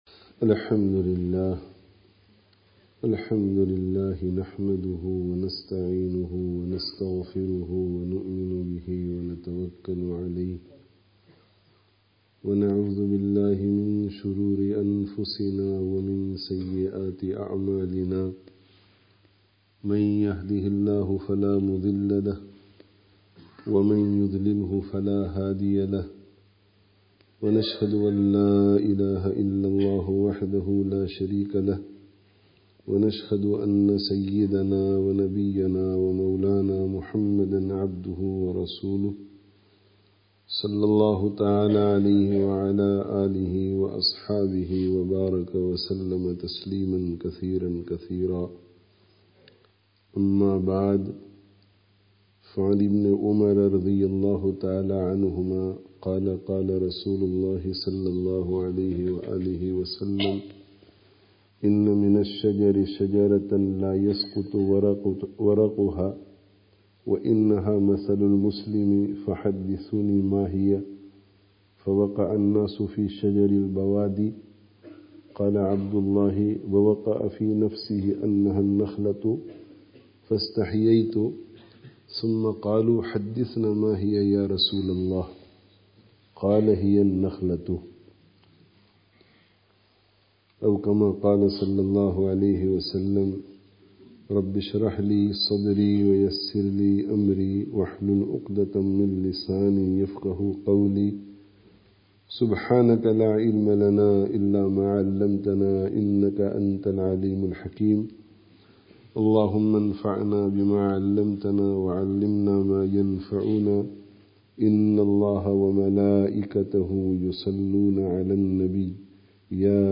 Dars of Hadith